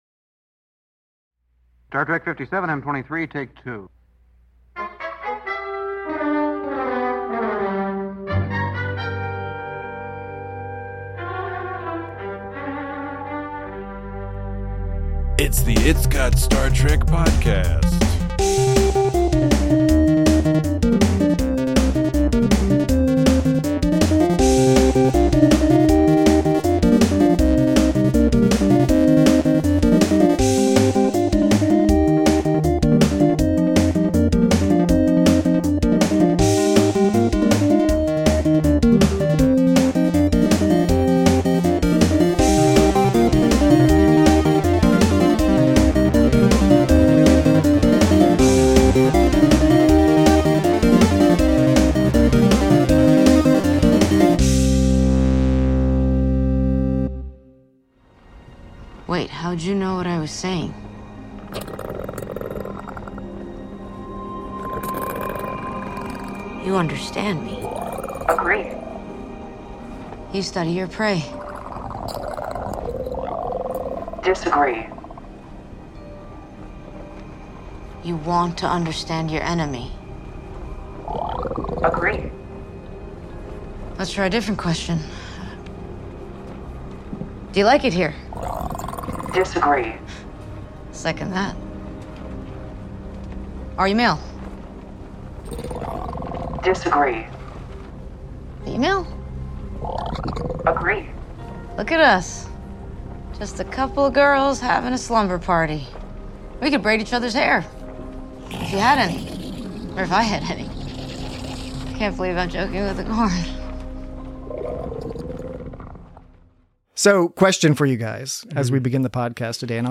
Join your comforted hosts as they discuss all aspects of this long-awaited and much-desired Erica Ortegas-centric episode, including the continuing value of familiar plotlines (at least when they are well-produced), superfluous fanbait that threatens to distract from a solid tale, and the universal appeal of a monster with its nose sticking out of a blanket flopped on its head.